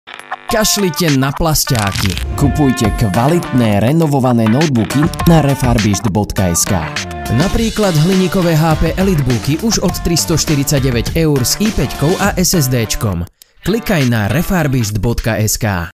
Profesinálny mužský VOICEOVER v slovenskom jazyku